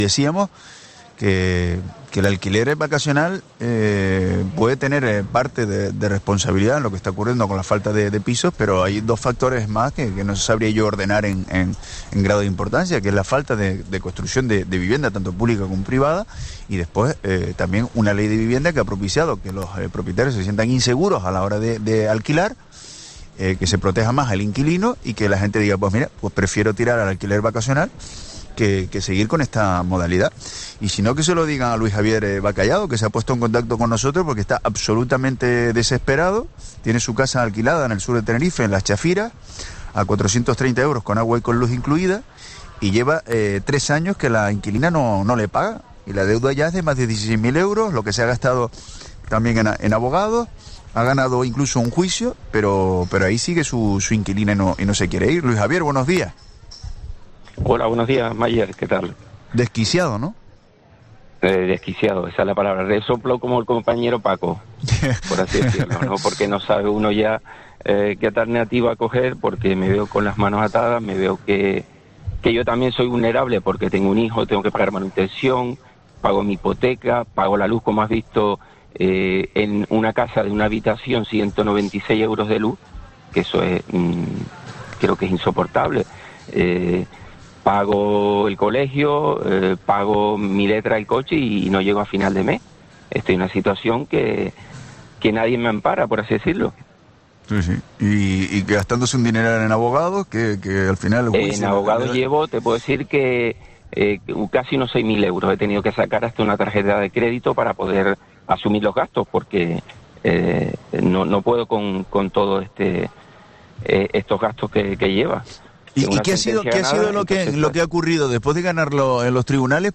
El afectado explicó en los micrófonos de Herrera en COPE Canarias